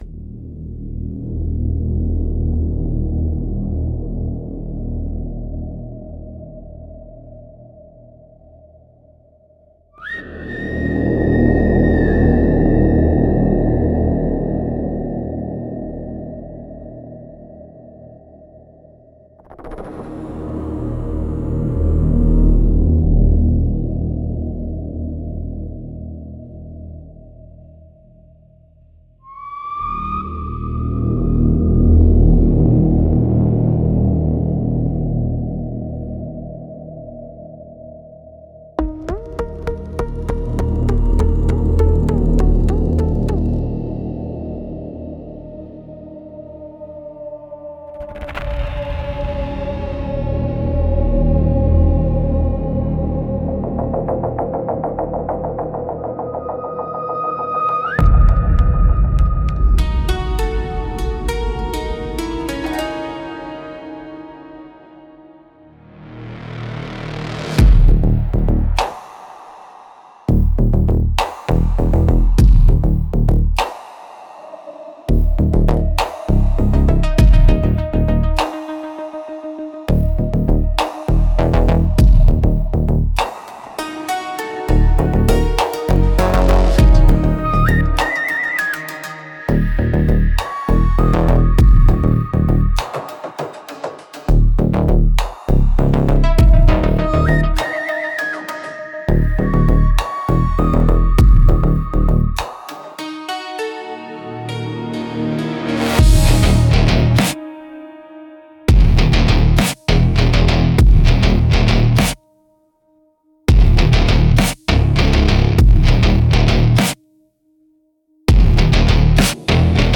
Instrumentals - Echoes of a Dying Industry